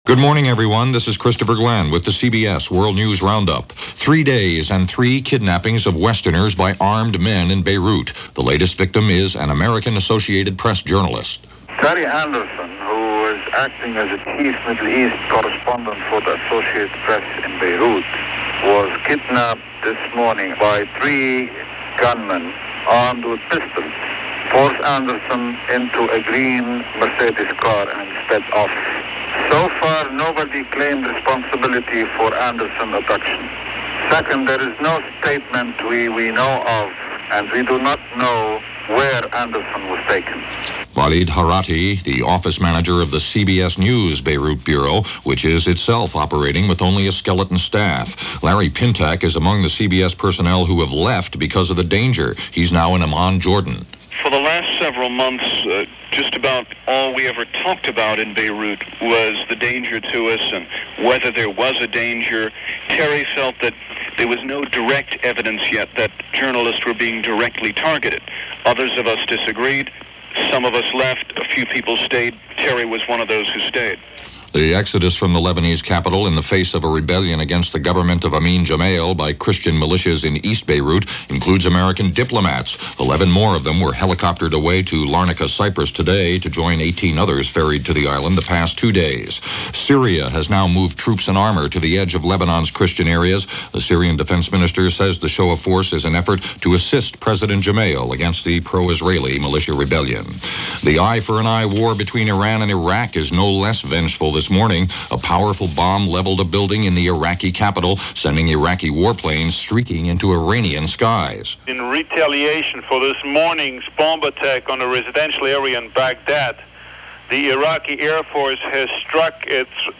All this, and a lot more for March 16, 1985 as reported by The CBS World News Roundup.